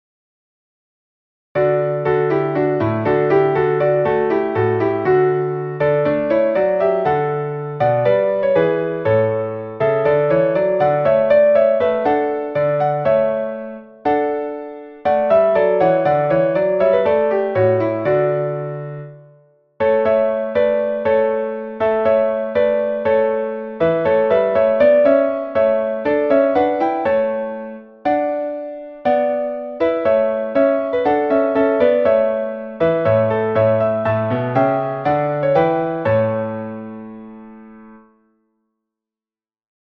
Meter: 8.8.8.8
Key: D Major